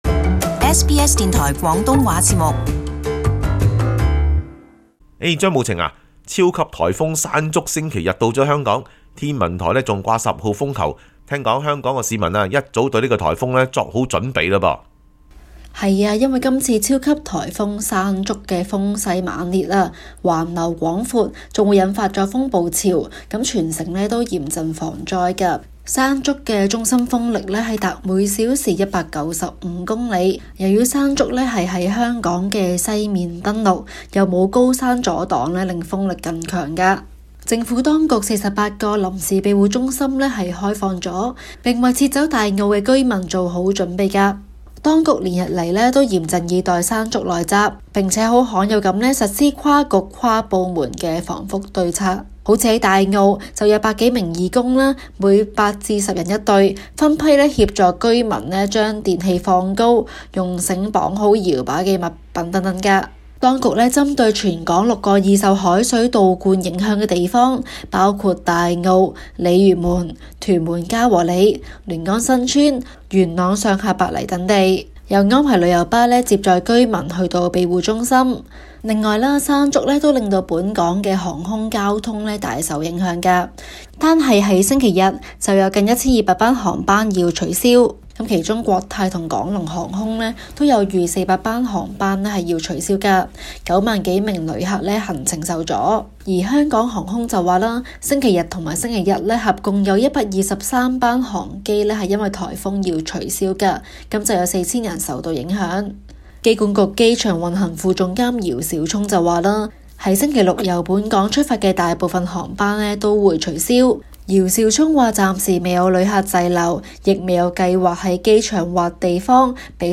【中港快訊】超級颱風【山竹】橫掃香港